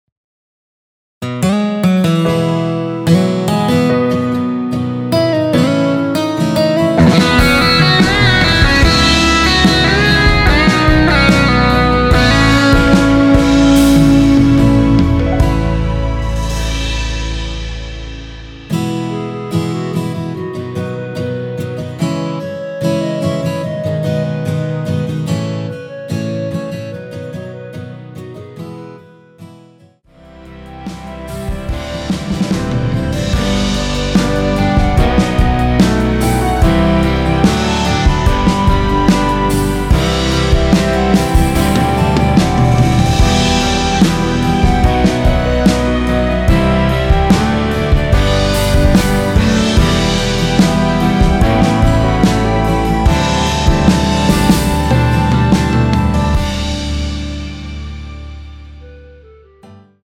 원키에서(-3)내린 (2절 삭제)멜로디 포함된 MR입니다.
앞부분30초, 뒷부분30초씩 편집해서 올려 드리고 있습니다.
중간에 음이 끈어지고 다시 나오는 이유는